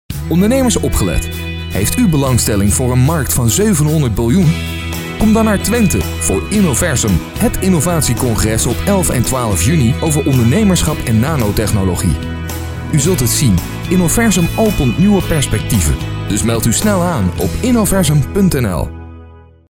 Demo’s
Commercial: